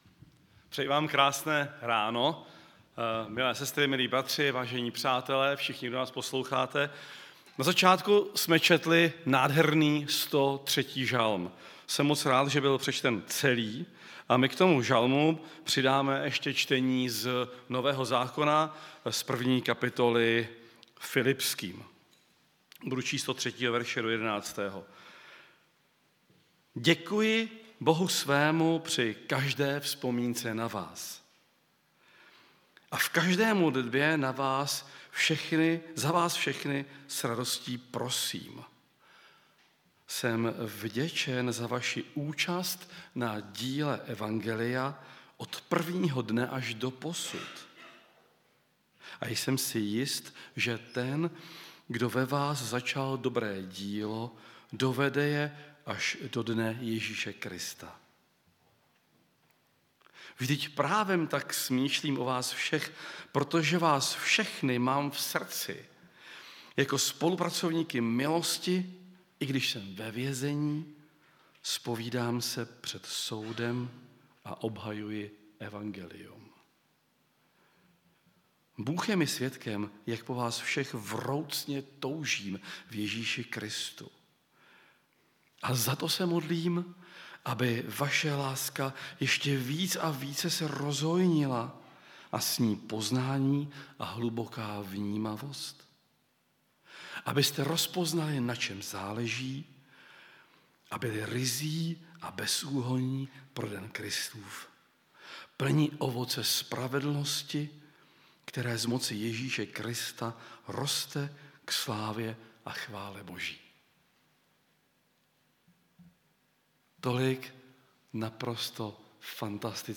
Kategorie: Nedělní bohoslužby přehrát / pozastavit Váš prohlížeč nepodporuje přehrávání audio souborů. stáhnout mp3